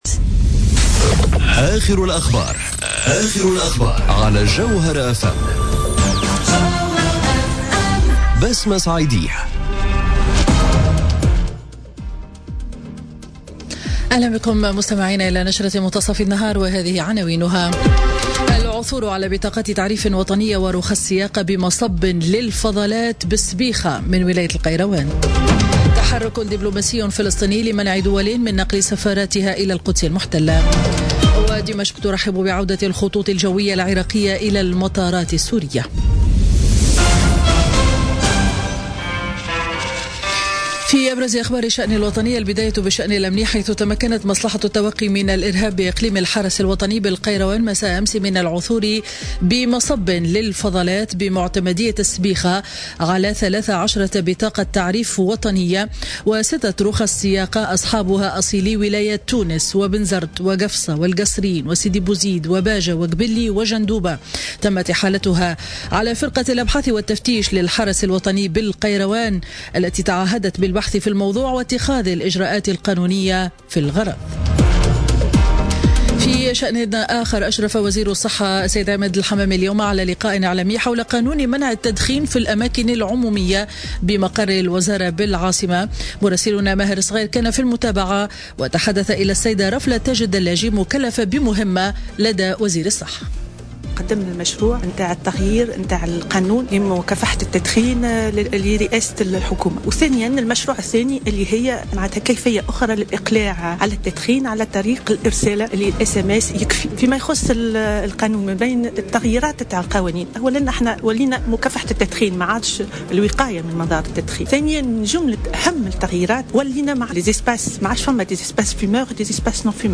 نشرة أخبار منتصف النهار ليوم الخميس 28 ديسمبر 2017